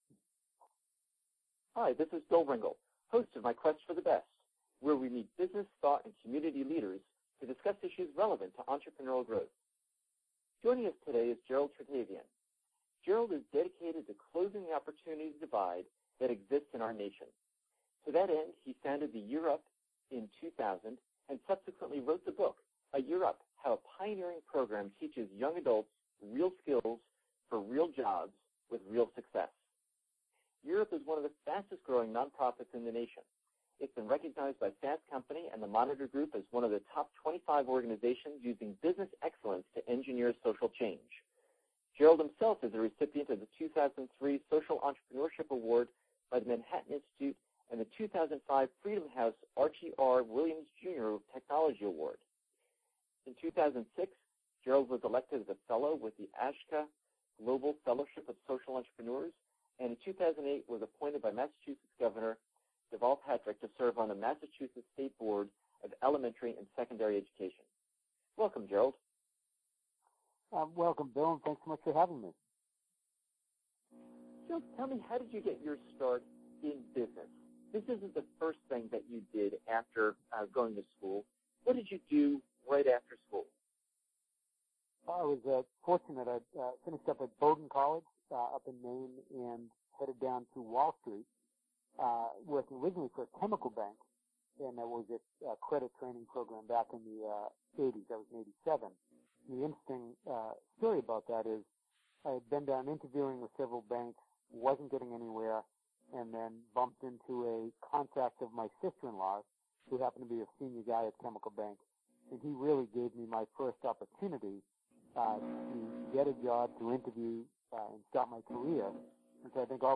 Listen to this interview to learn: What he is doing to redefine who is talented.